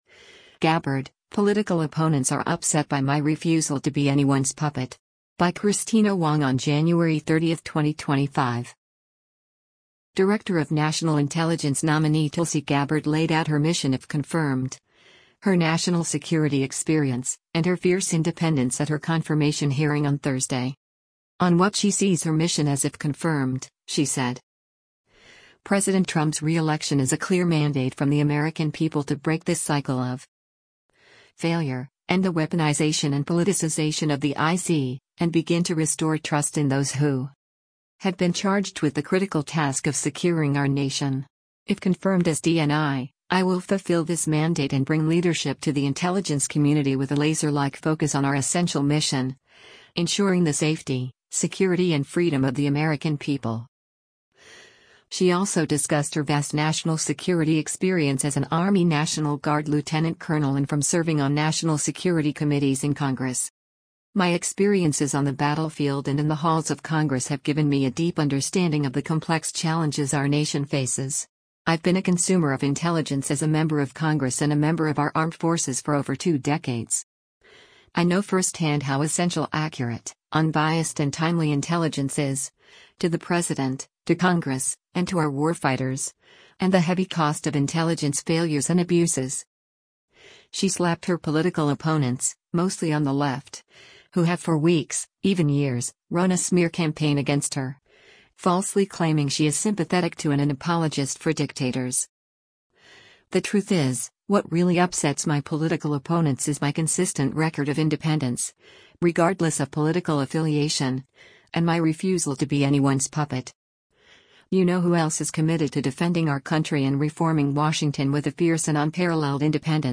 Director of National Intelligence Nominee Tulsi Gabbard laid out her mission if confirmed, her national security experience, and her fierce independence at her confirmation hearing on Thursday.